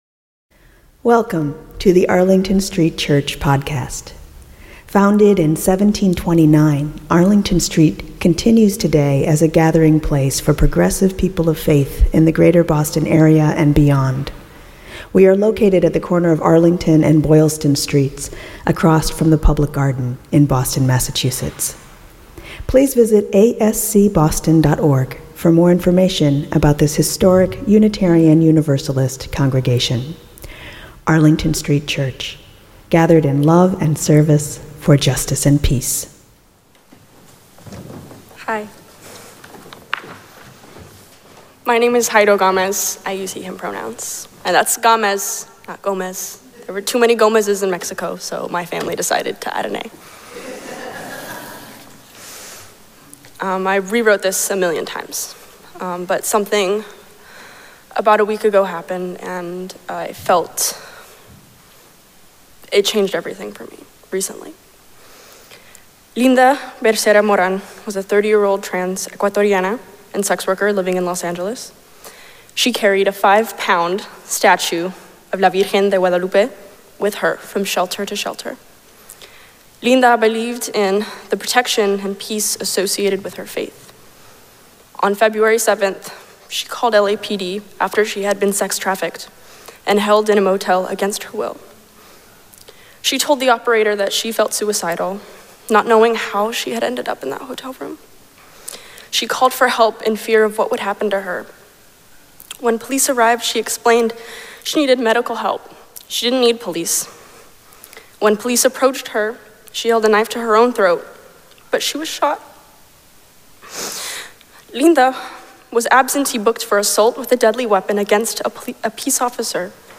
The sermon podcast from Arlington Street Church delivers our weekly sermon to listeners around the world.